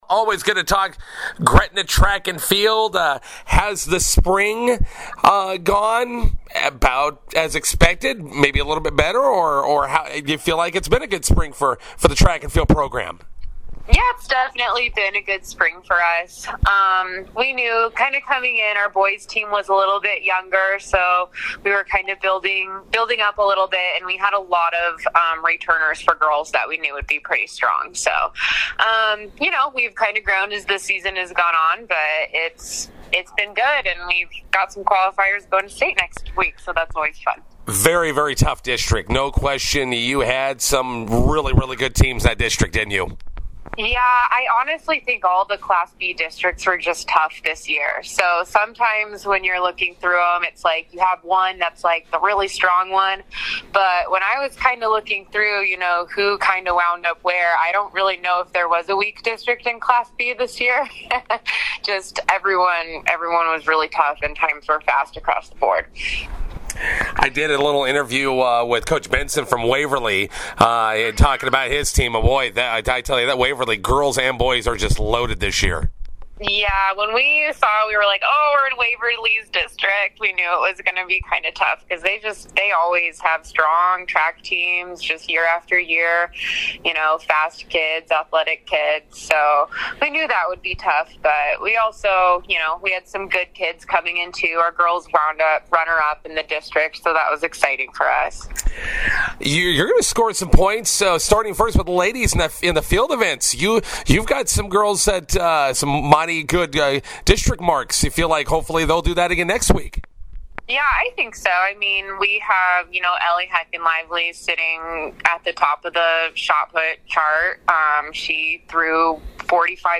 INTERVIEW: Gretna girls/boys track and field teams preparing for the Class B state meet next week.